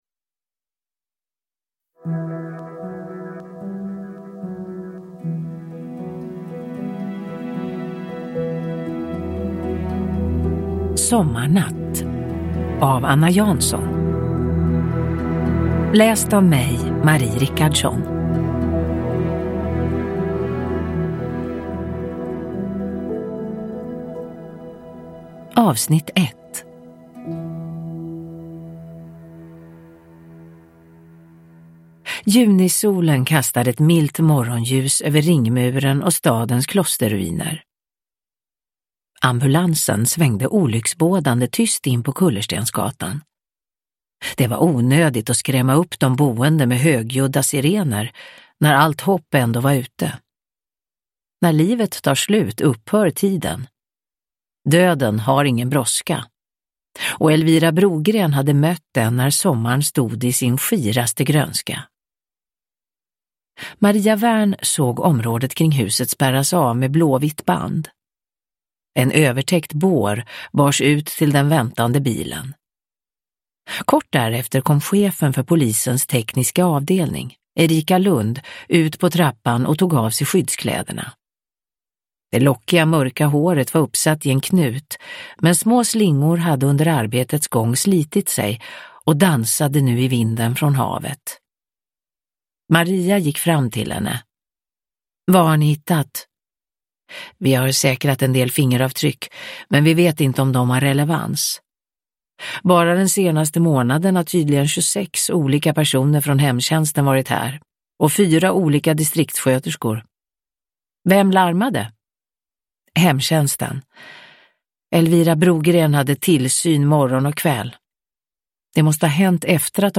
Sommarnatt - 1 – Ljudbok – Laddas ner
Uppläsare: Marie Richardson